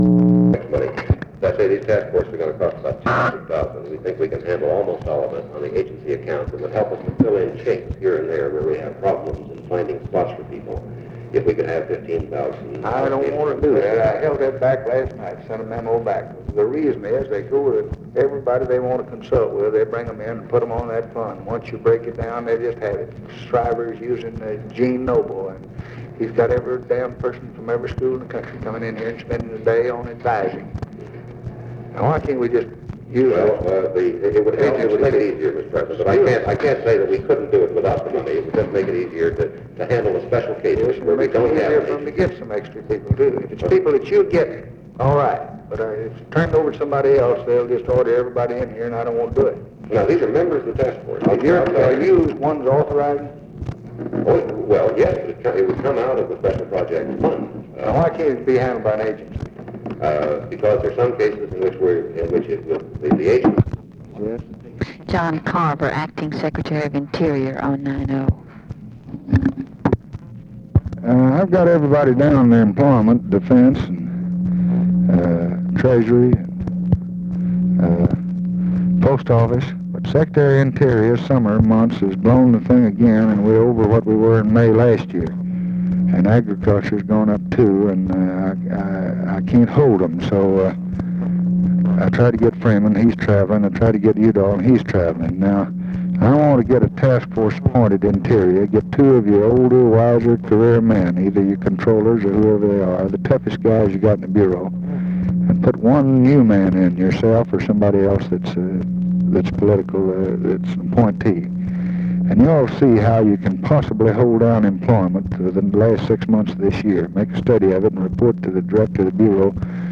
Conversation with JOHN CARVER and OFFICE CONVERSATION, July 14, 1964
Secret White House Tapes